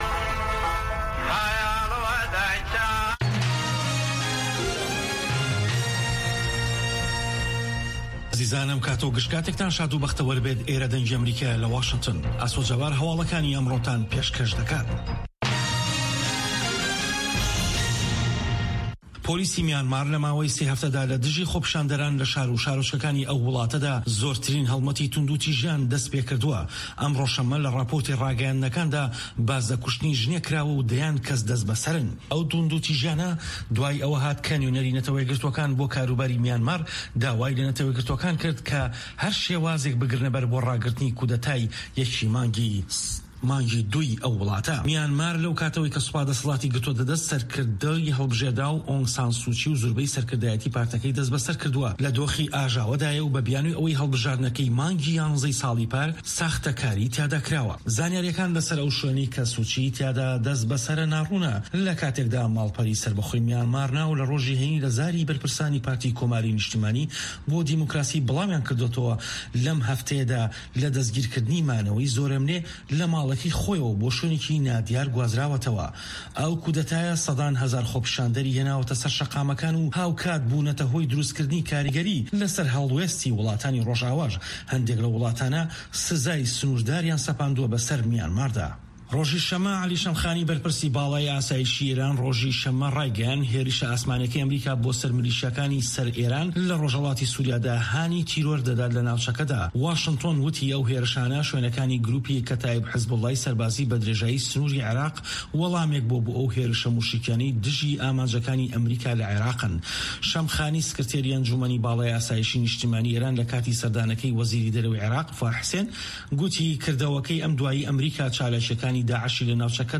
هه‌واڵه‌کان ، ڕاپـۆرت، وتووێژ.